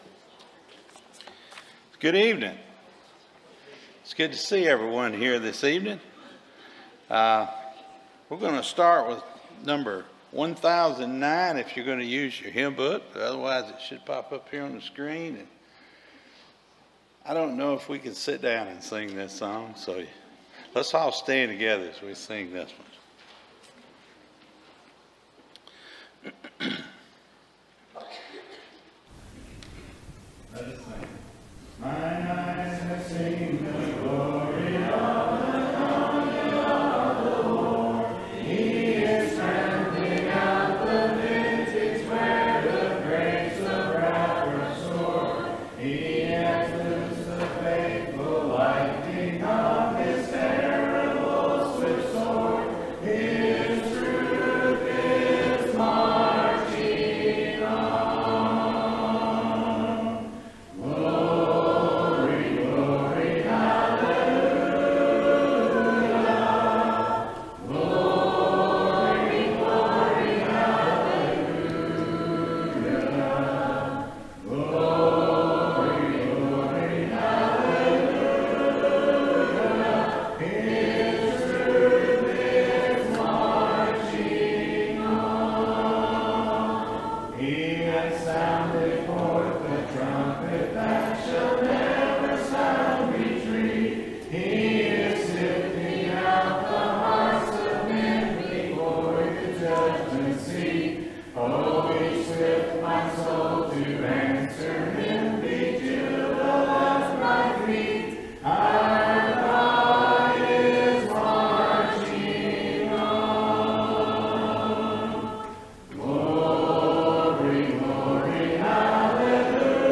Jonah 2:7-9 English Standard Version Series: Sunday PM Service